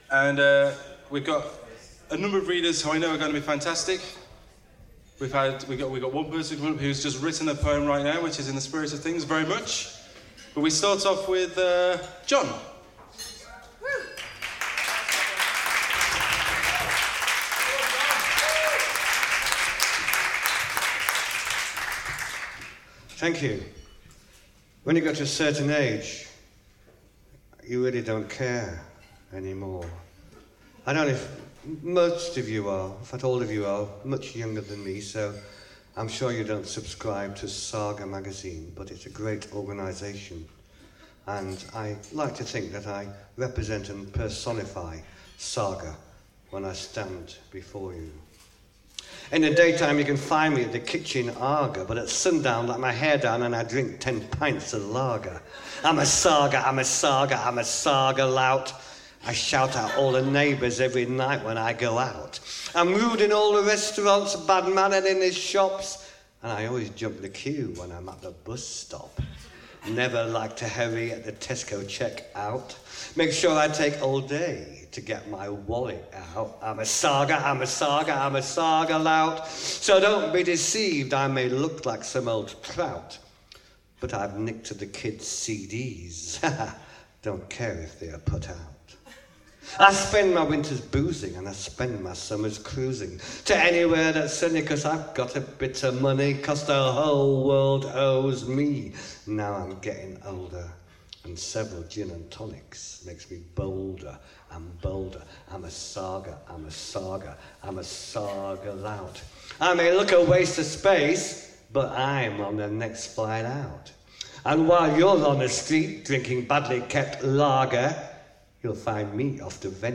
Spoken Word - Saga Louts